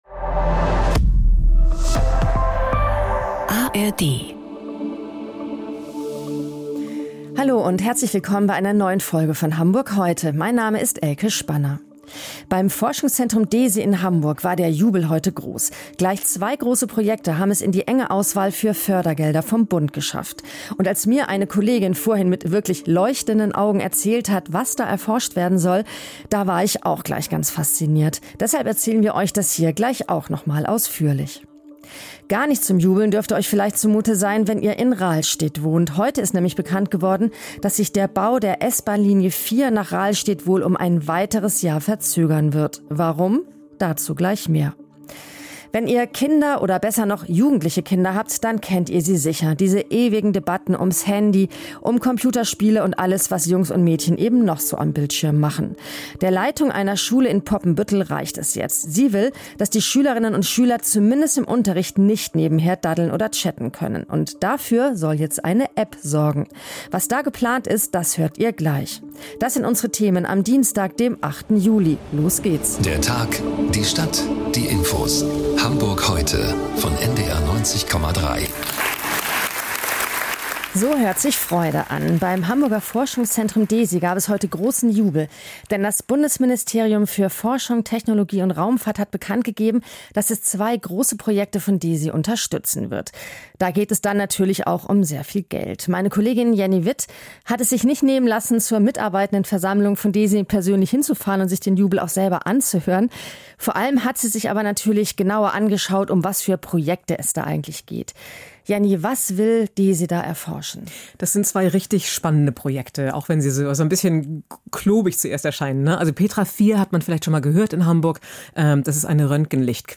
Nachrichten - 09.07.2025